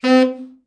TENOR SN  17.wav